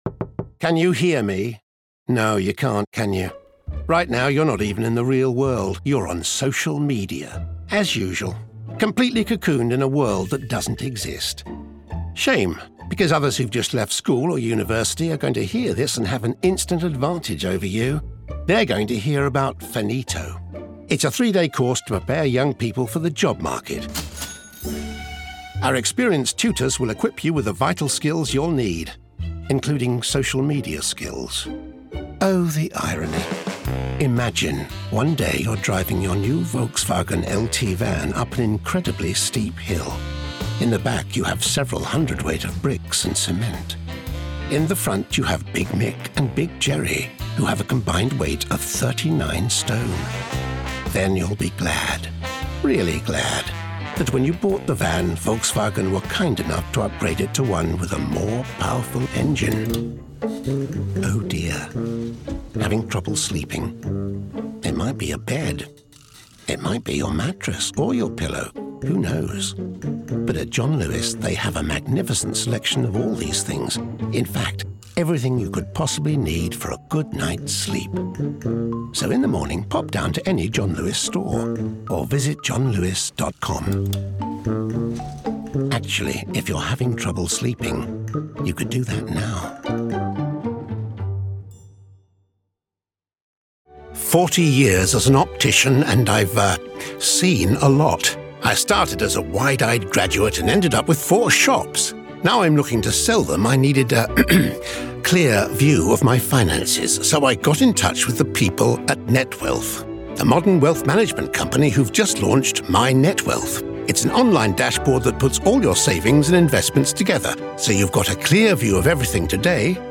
Some of Peter's voice credits include the Doctor Who podcast series and the video game Lies of P. His voice performances have a very human and natural read, which makes him a particularly ideal casting if you're looking for a warm and welcoming voice – one we all know and love.
Voice Reels Commercial
Natural accents Neutral, RP
PeterDavisonCommericalJan2025.mp3